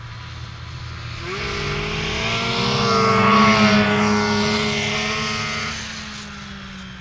Snowmobile Description Form (PDF)
Subjective Noise Event Audio File (WAV)